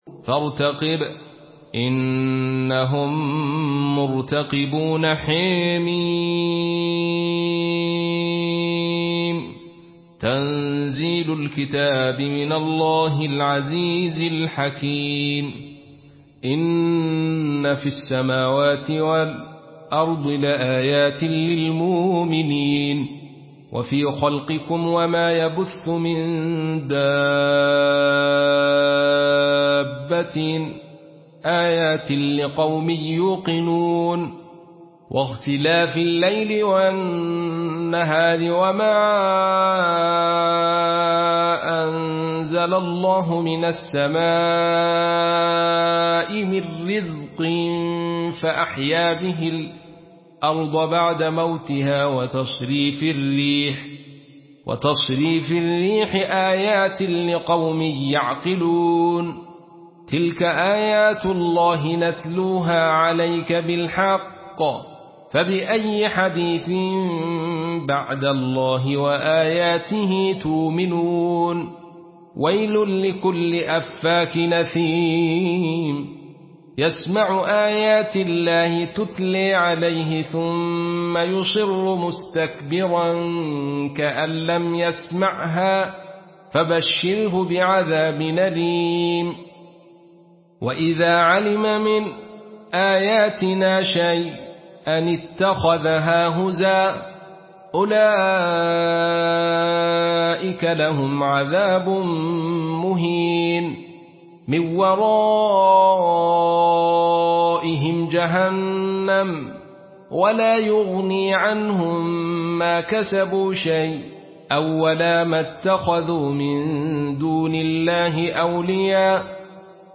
Surah আল-জাসিয়া MP3 by Abdul Rashid Sufi in Khalaf An Hamza narration.
Murattal